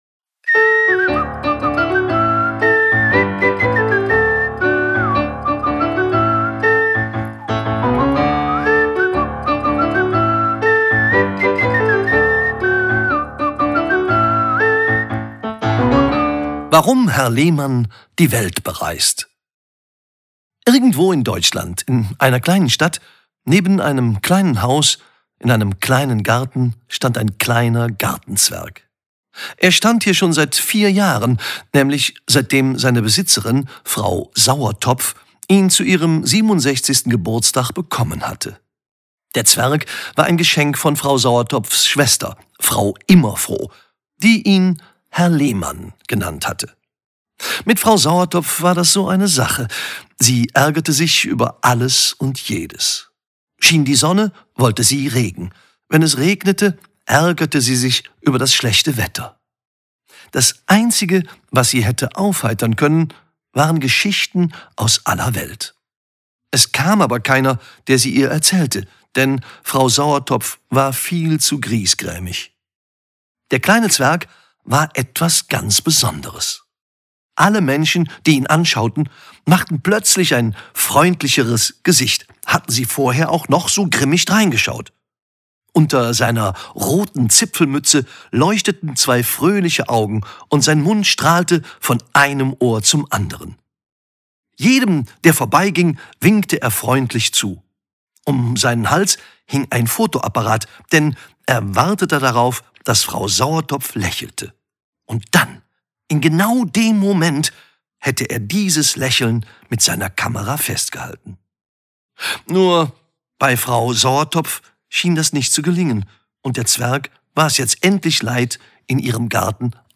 Hörbuch
Henning Krautmacher (Sprecher)